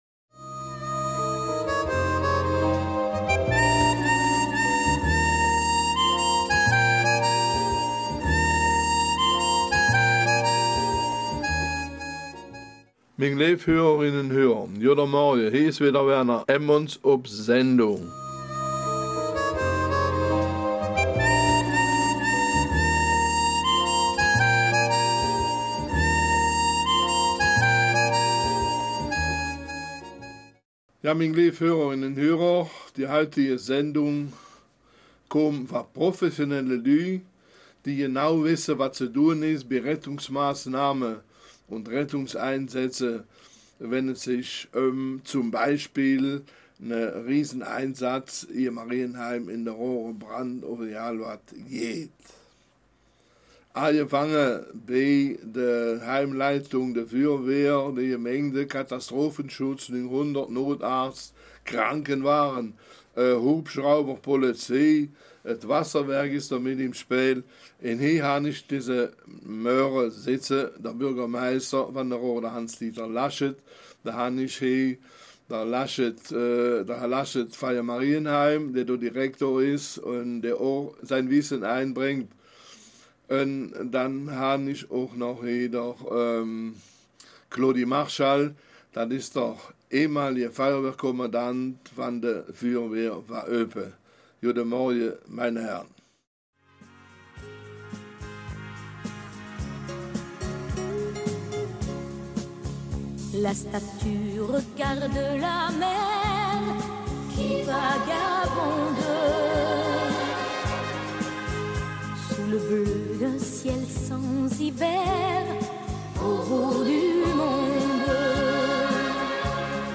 Raerener Mundartsendung zum Thema Katastropheneinsatz